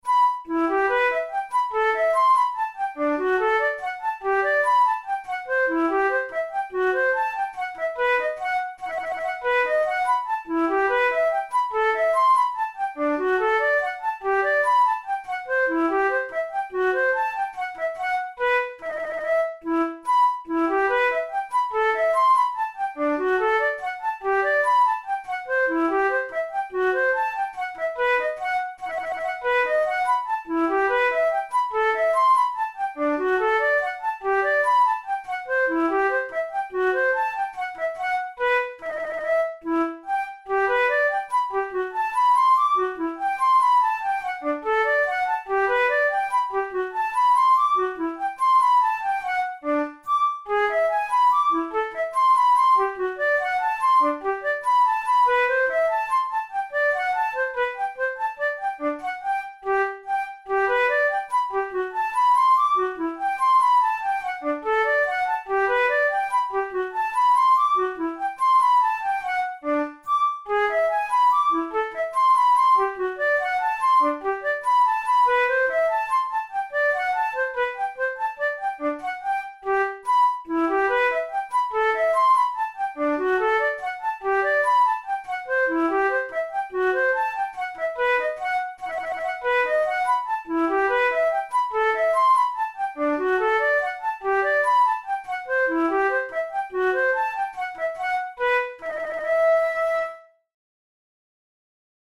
Baroque, Minuets, Written for Flute